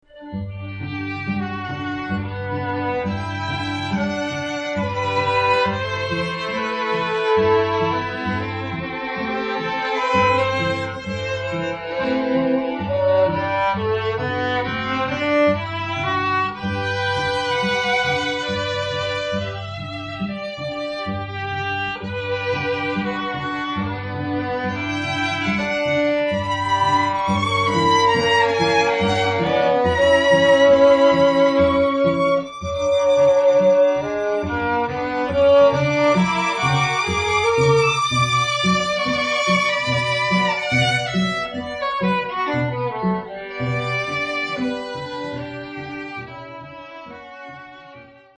o  There is very little difference in volume between a Trio and a Quartet, but they have a different sound.
String Quartet
Swan Quartet short.mp3